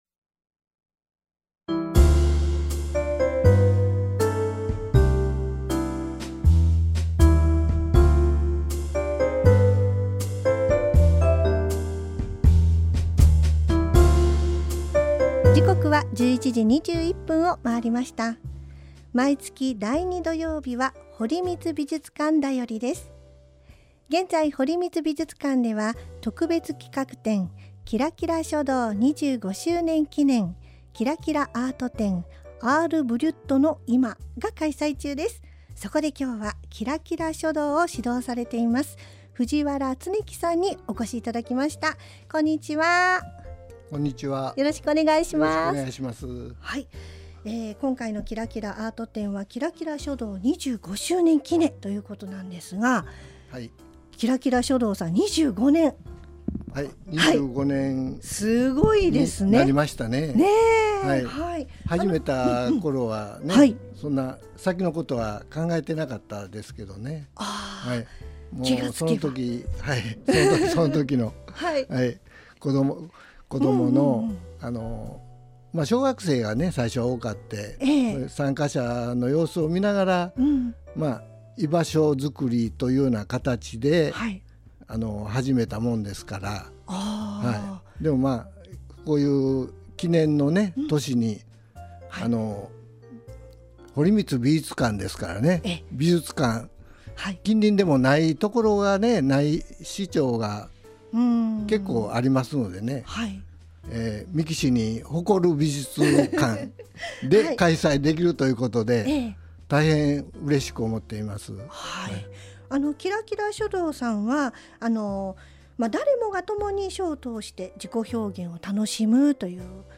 エフエムみっきい📻に出演